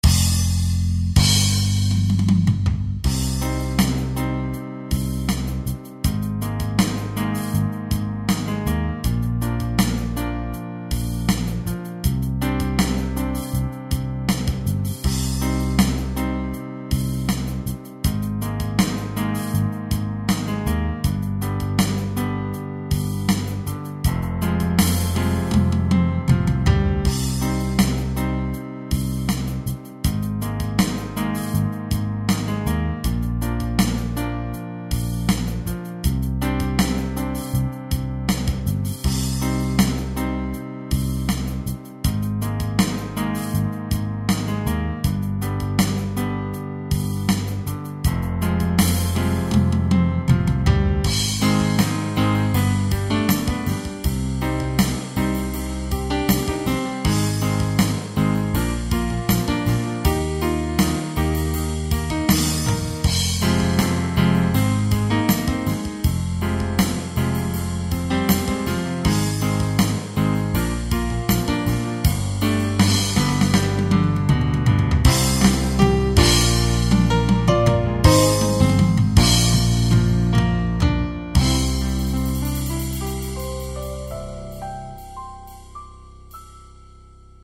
Meine Damen und Herren, hier ist er, der Backingtrack für unseren Aussen-Jam #1 .